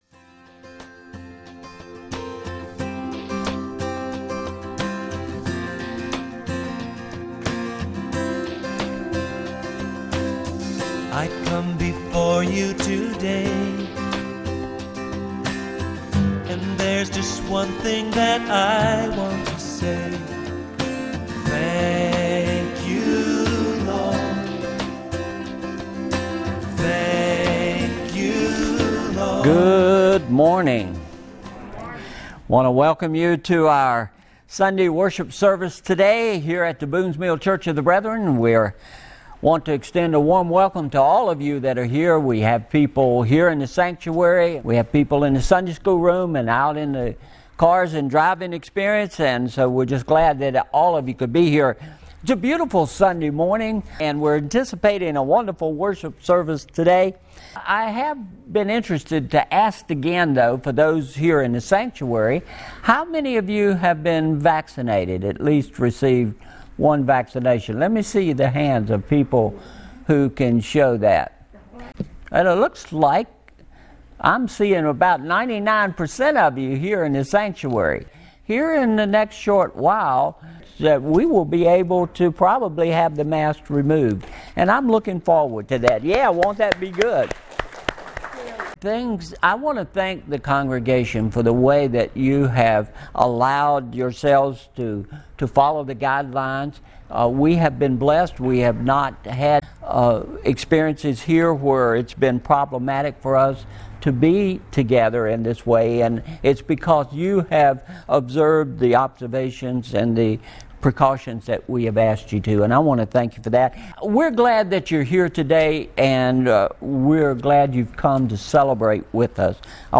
May 02, 2021 How to Pray about Your Problems MP3 Notes Discussion Sermons in this Series Sermon Series How to Face Our Future!